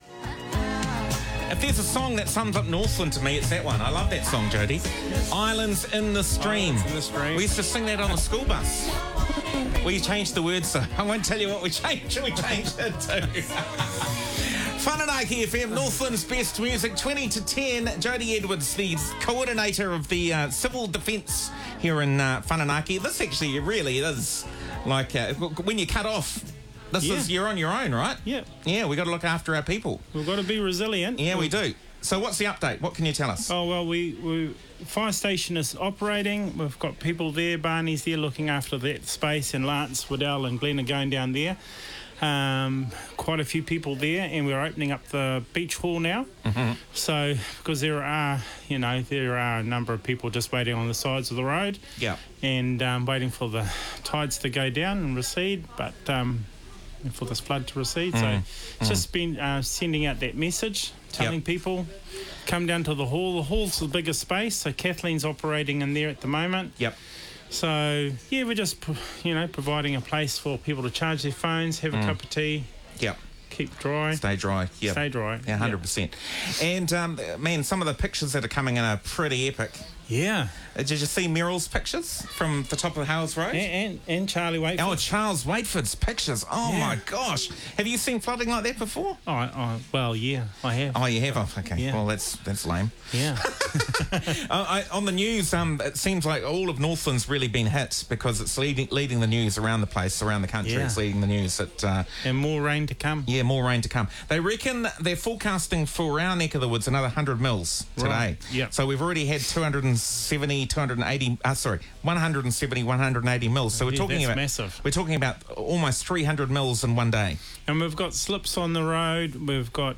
Music selection fits the moment:
Interview
Overall, a great example of what “live, local radio” actually sounds like - imperfect, human, community-focused and invaluable during significant local events.
When Whananaki FM goes live to air from a general store, its studio was not fully soundproofed.